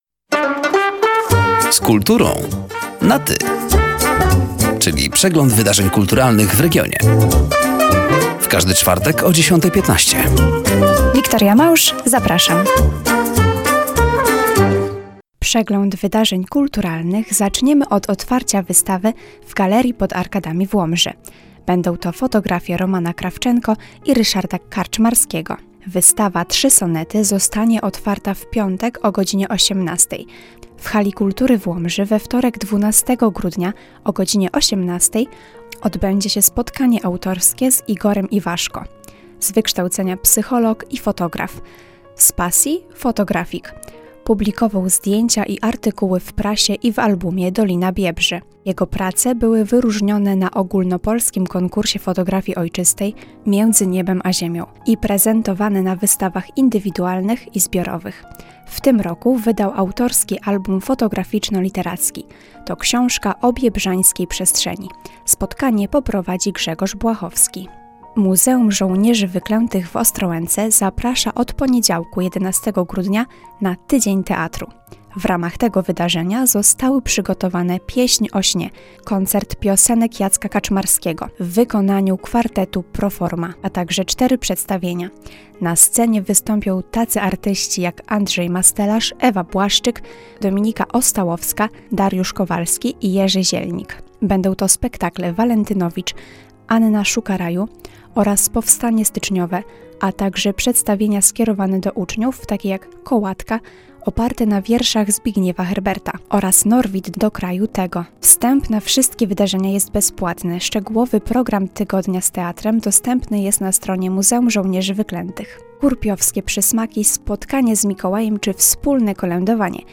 Zapraszamy do wysłuchania rozmowy oraz zapoznania się z wydarzeniami kulturalnymi.